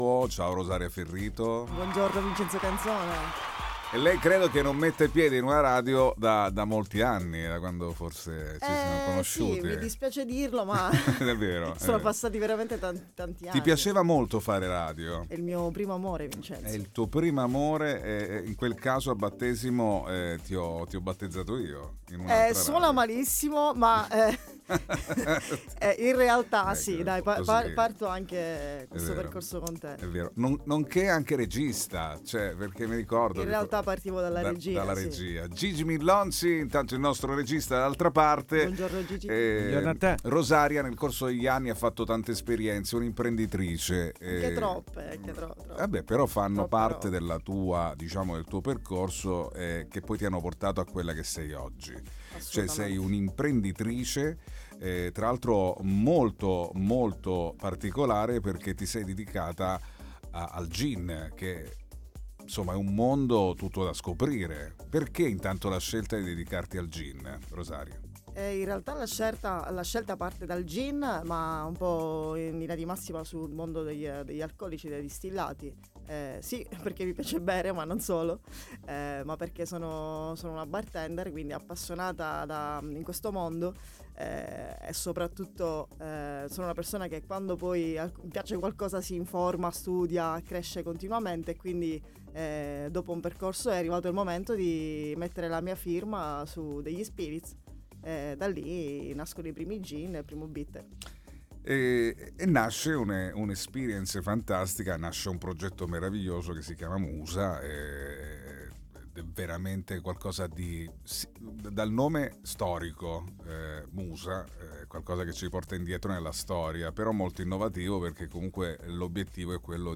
All Inclusive Interviste 19/01/2026 12:00:00 AM